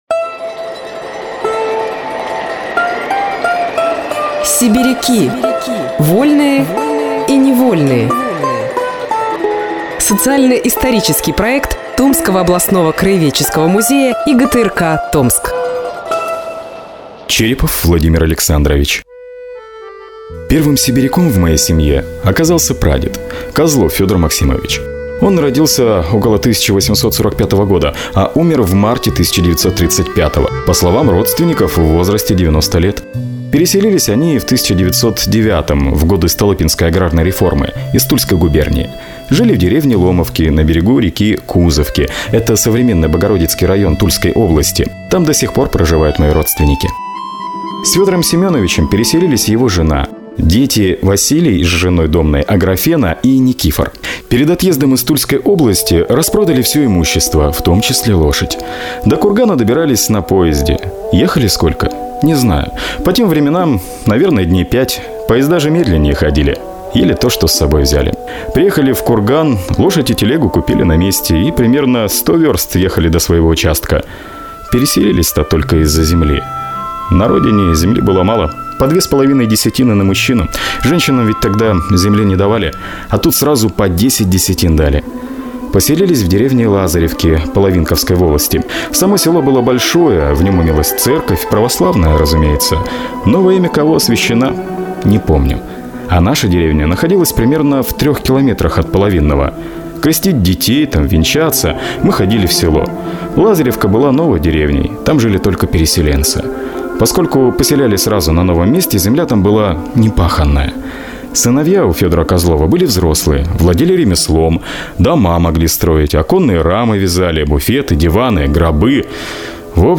Мужской голос
Женский